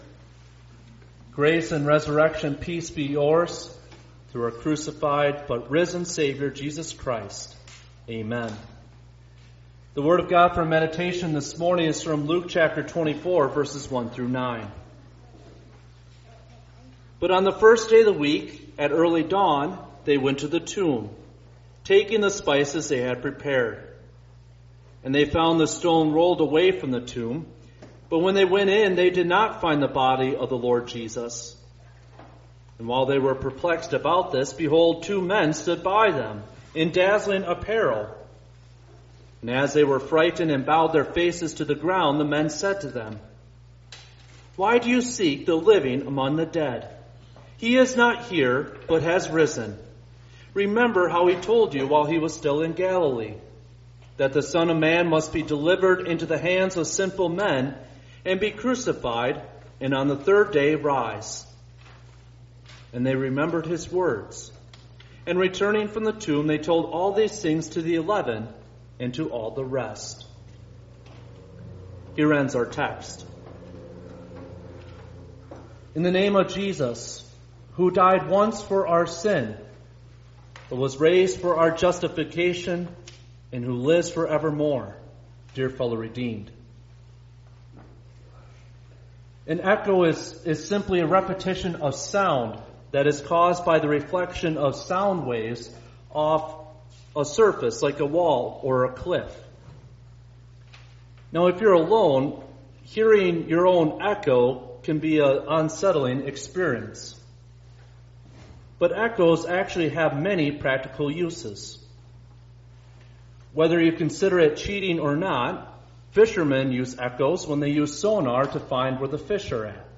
Easter-Sunday.mp3